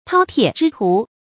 饕餮之徒 注音： ㄊㄠ ㄊㄧㄝ ˋ ㄓㄧ ㄊㄨˊ 讀音讀法： 意思解釋： 比喻貪吃的人。